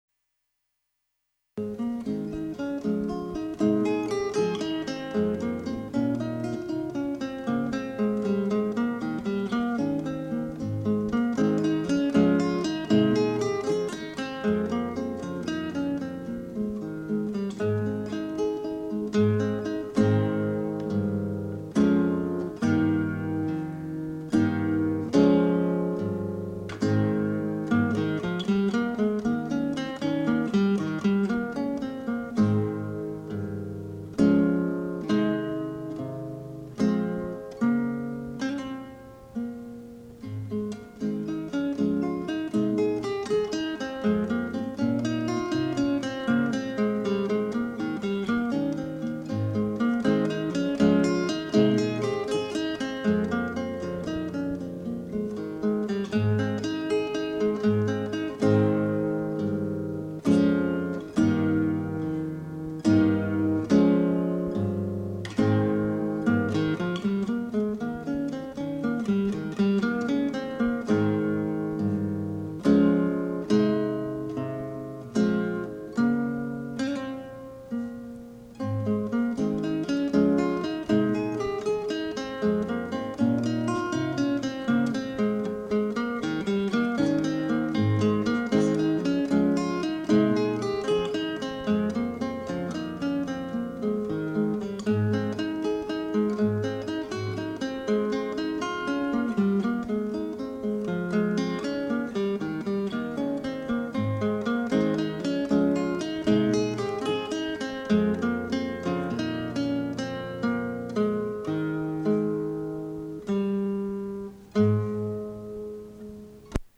This guitarist performs the standards for your wedding or social event on the classical acoustic guitar, and can play subtle "jazz" electric guitar for your cocktail hour or other intimate gathering.
guitar